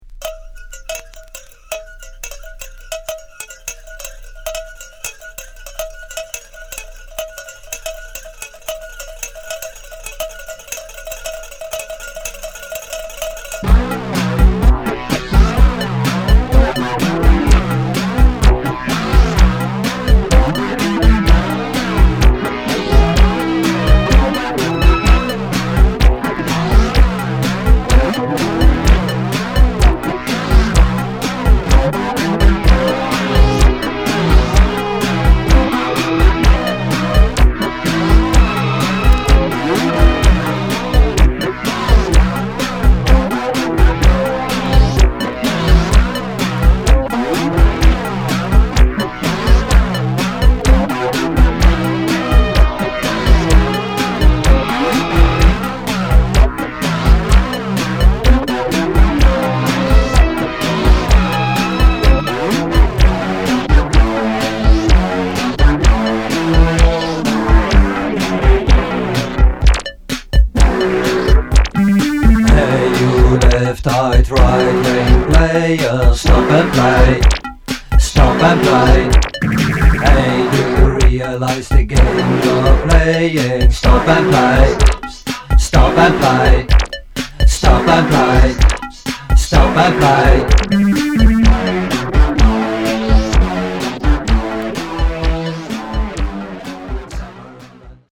ポストパンク経由のむきだしのミニマリズムに、サイケデリックな浮遊感を伴うシンセやギターのひずんだ轟音を注ぎ込んだ全6曲。
キーワード：ミニマル　宅録　脱線パンク